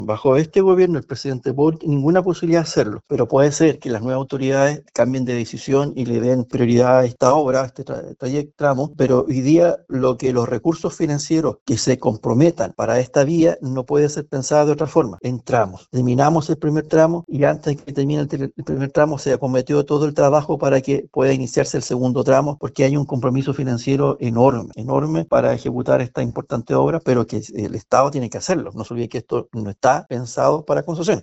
No obstante, el seremi de Obras Públicas, Juan Alvarado, indicó que esta opción podría evaluarse durante la próxima administración del presidente electo, José Antonio Kast.